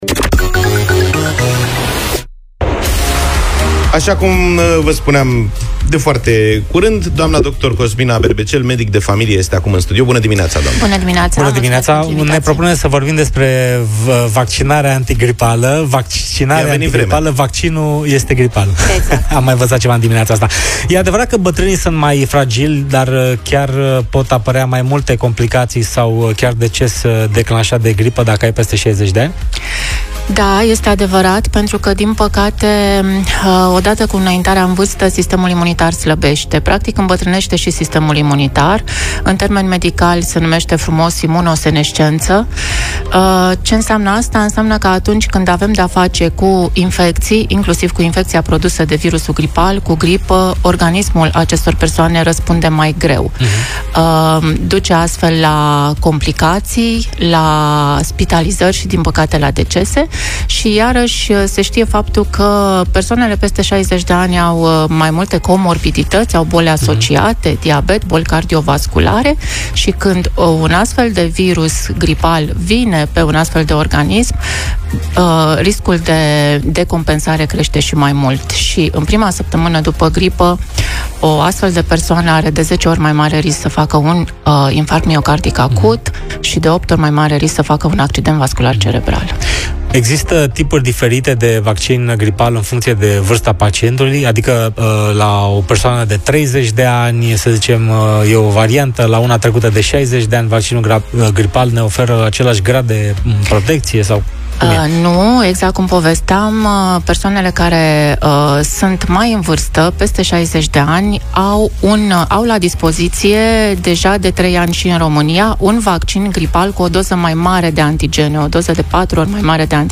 Interviul a fost transmis în direct miercuri dimineață, în „Deșteptarea” – matinalul Europa FM.